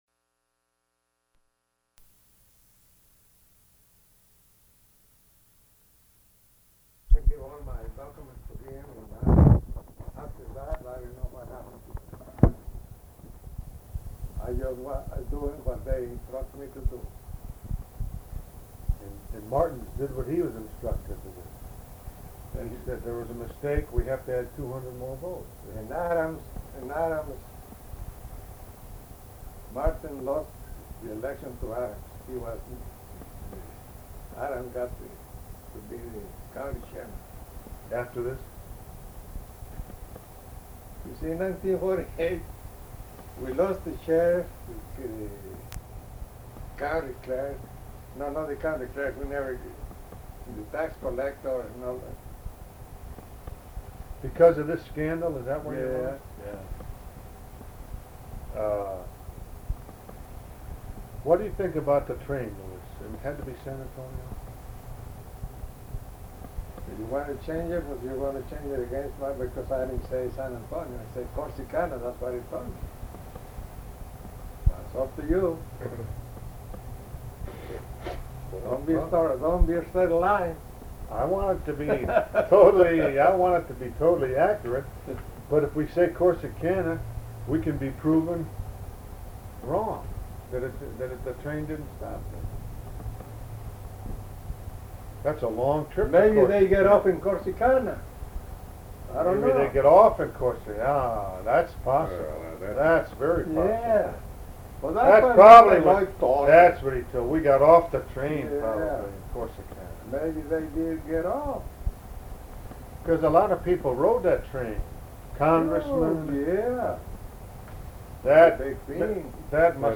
Format Audio tape
Specific Item Type Interview Subject Congressional Elections Texas